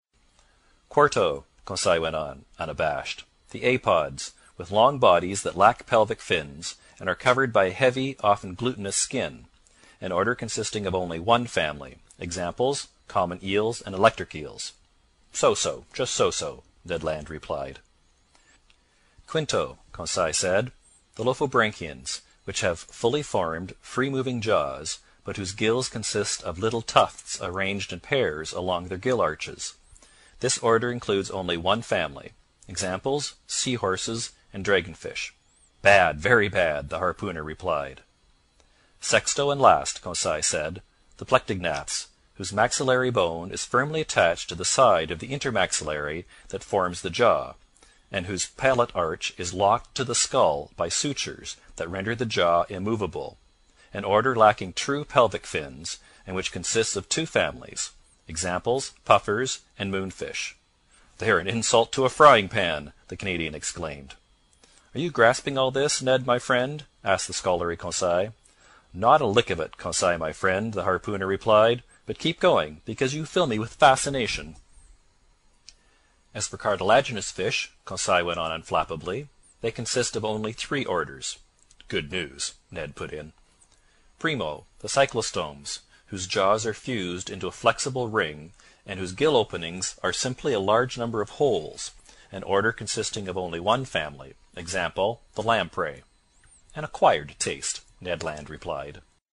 英语听书《海底两万里》第195期 第14章 黑潮暖流(9) 听力文件下载—在线英语听力室